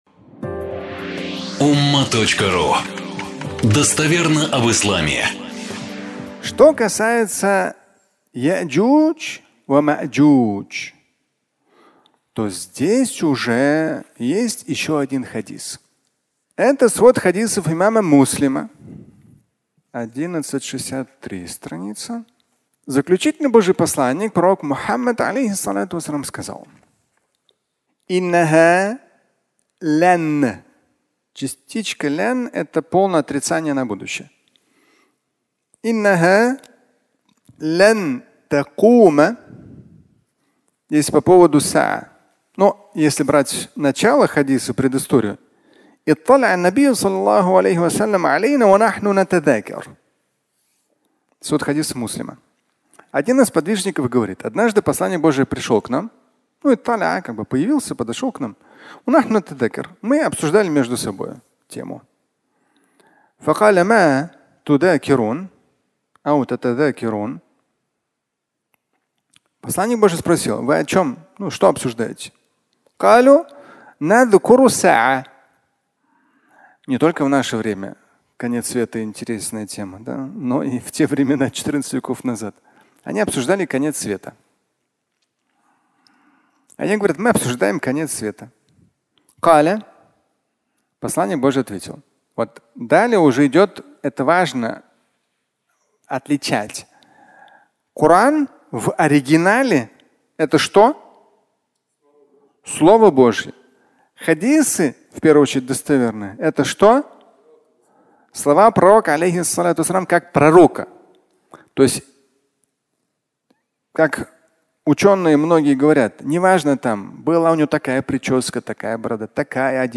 Фрагмент пятничной лекции